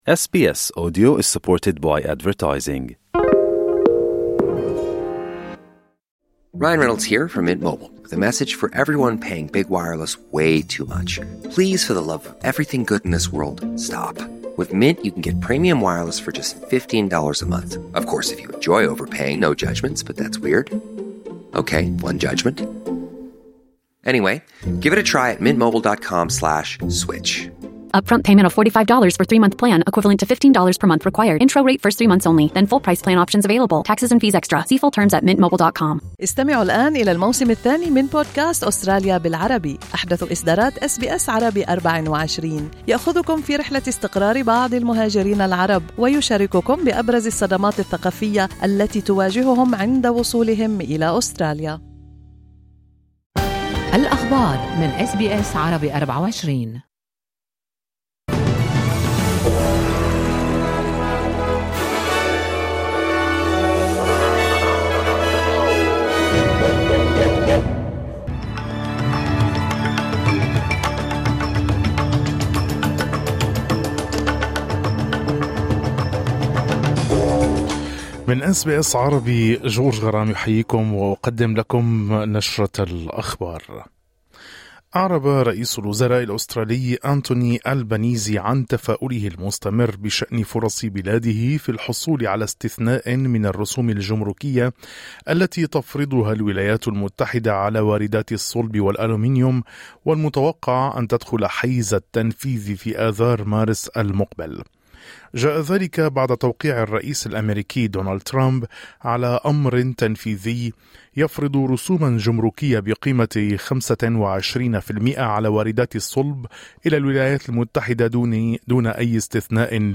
نشرة أخبار الظهيرة 12/02/2025